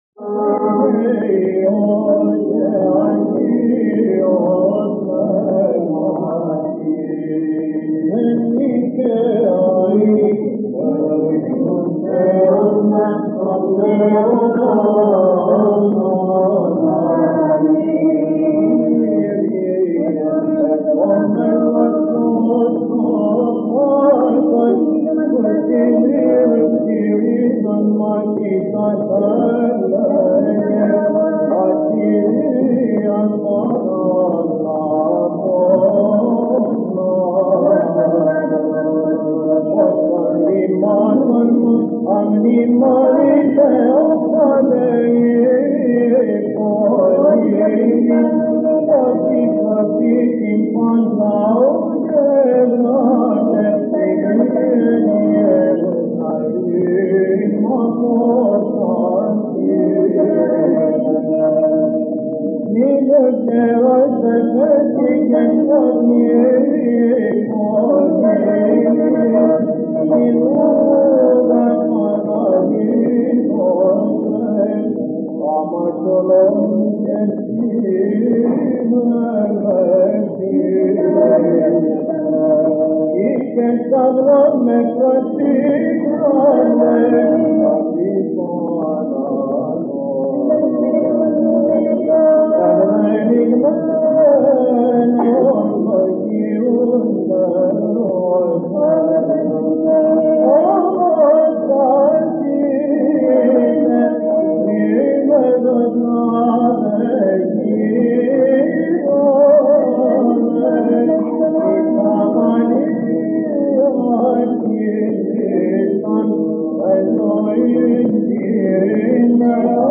(Δοξαστικόν)
(ἠχογρ. Κυρ. Βαΐων ἑσπέρας)